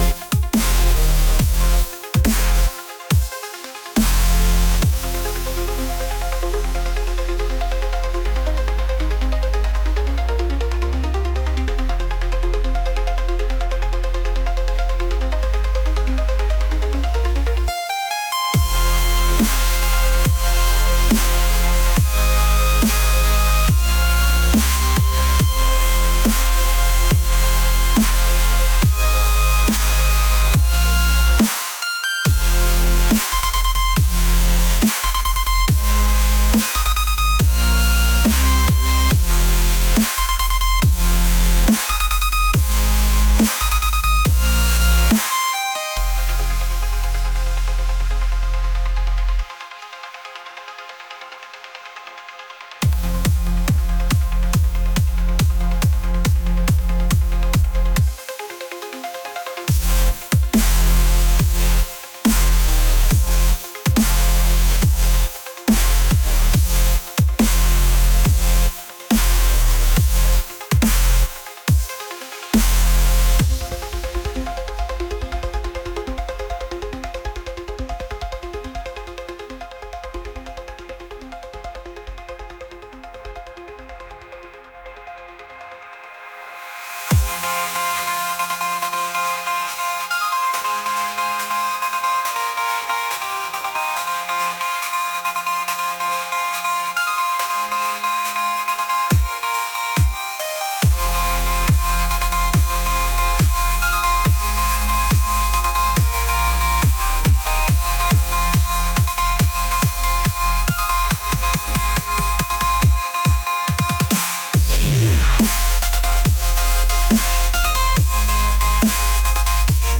electronic | intense